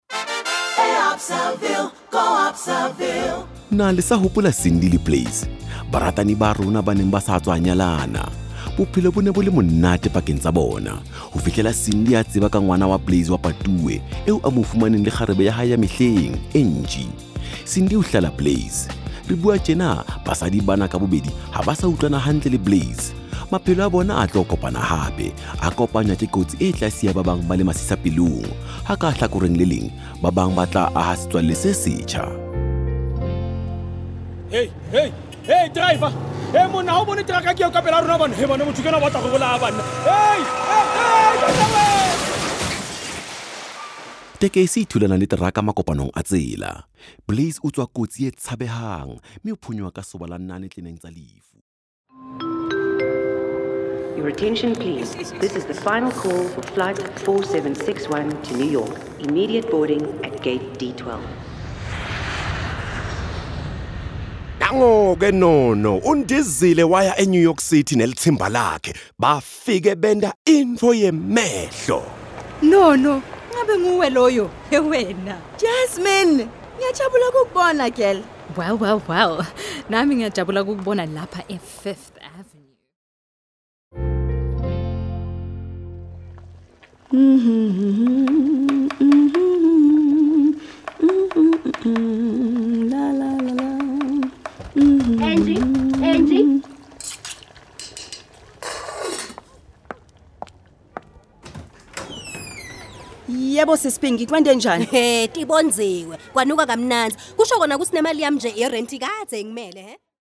(Radio Drama Series)